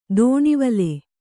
♪ dōṇivale